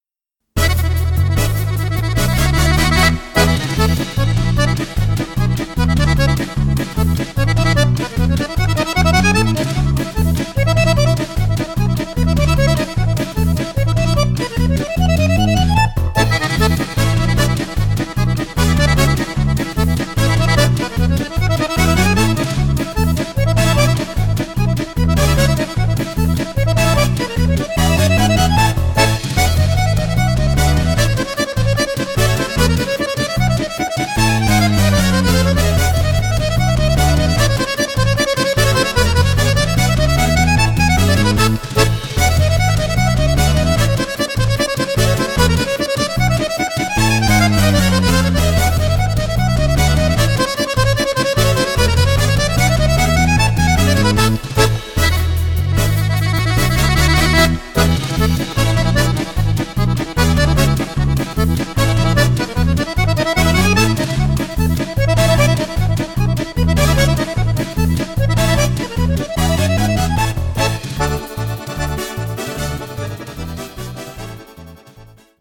Allegro
for accordion